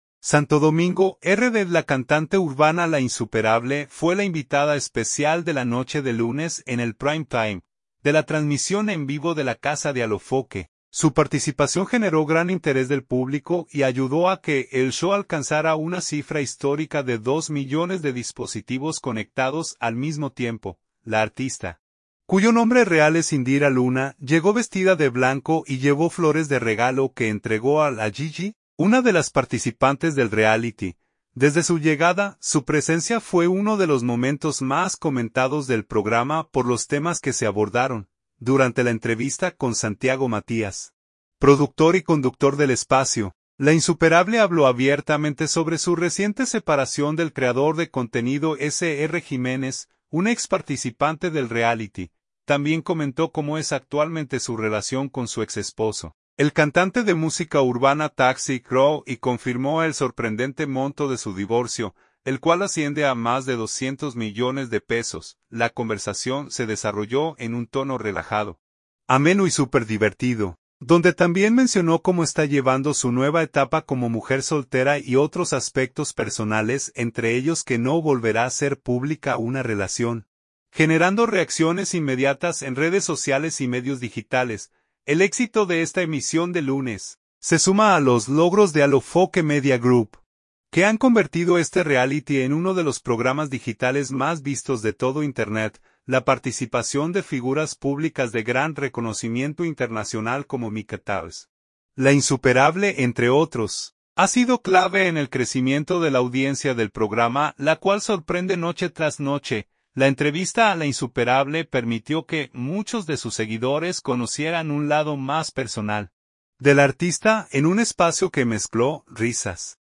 Santo Domingo, RD.- La cantante urbana La Insuperable fue la invitada especial de la noche del lunes en el primetime, de la transmisión en vivo de La Casa de Alofoke.
La conversación se desarrolló en un tono relajado, ameno y súper divertido, donde también mencionó cómo está llevando su nueva etapa como mujer soltera y otros aspectos personales entre ellos que no volverá hacer pública una relación, generando reacciones inmediatas en redes sociales y medios digitales.